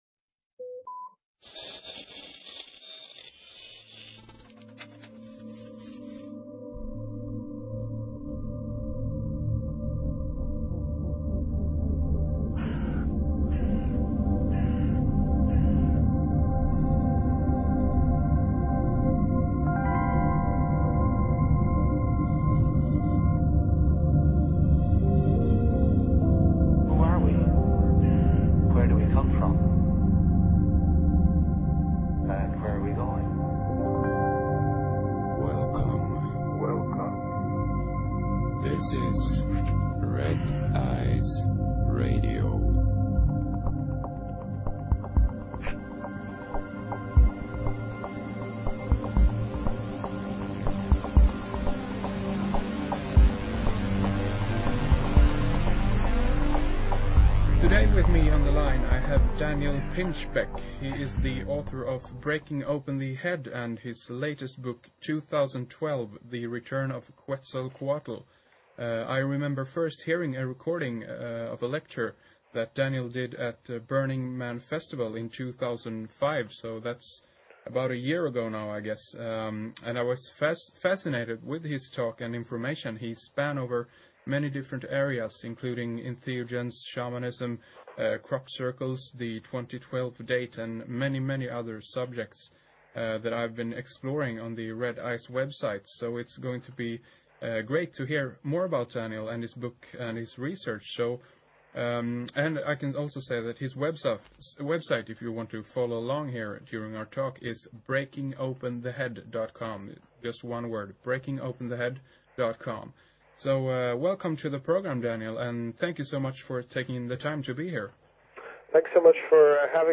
Talk Show Episode, Audio Podcast, Red_Ice_Radio and Courtesy of BBS Radio on , show guests , about , categorized as